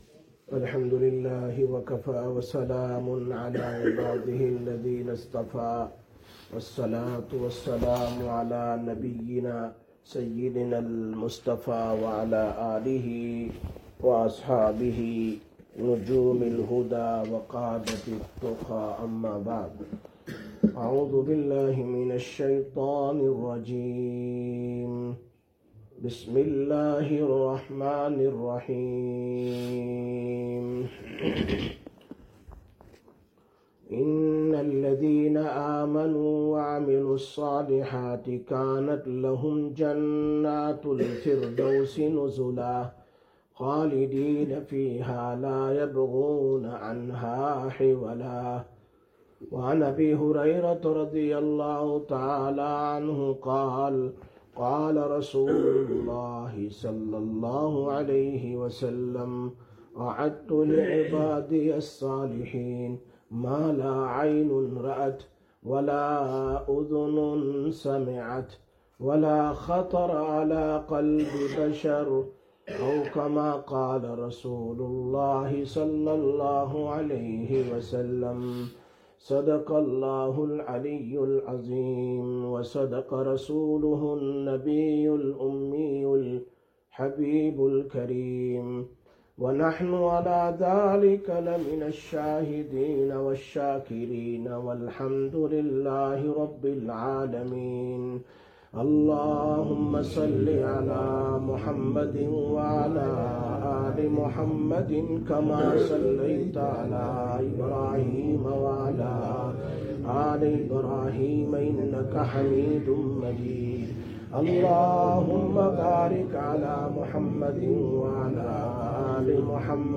12/09/2025 Jumma Bayan, Masjid Quba